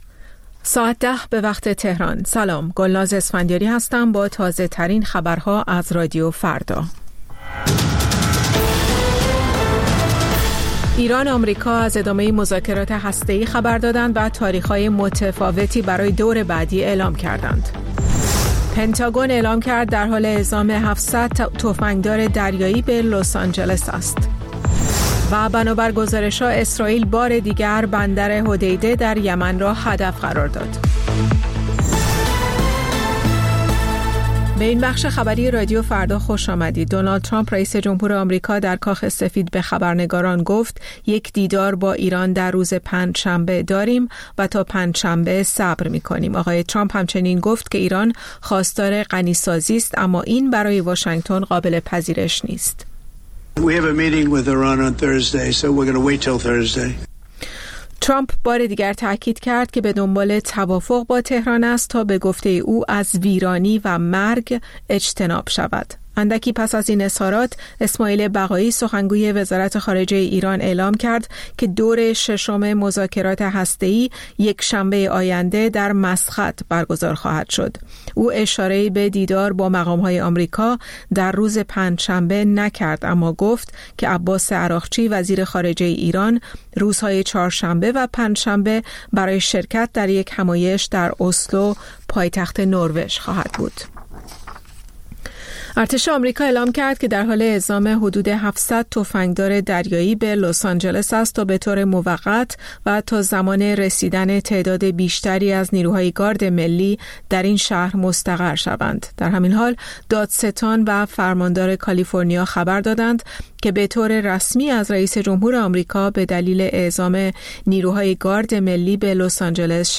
سرخط خبرها ۱۰:۰۰
پخش زنده - پخش رادیویی